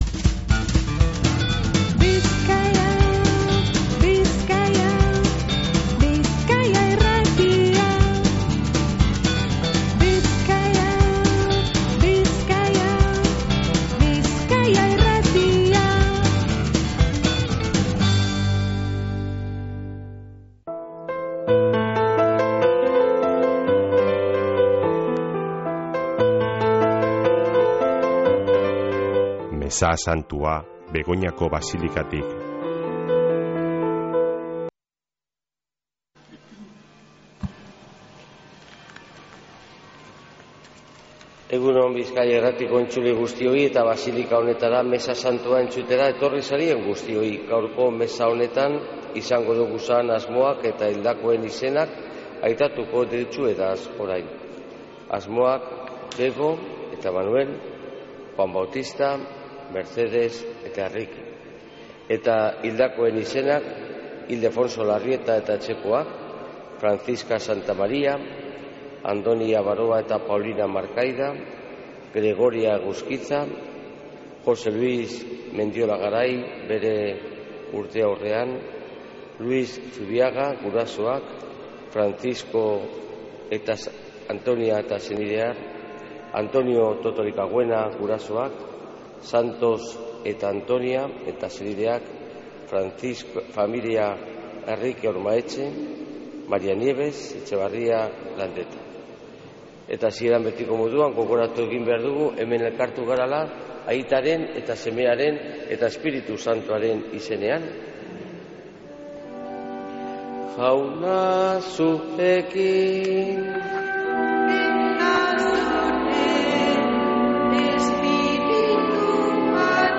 Mezea Begoñako basilikatik | Bizkaia Irratia
Mezea (25-05-14)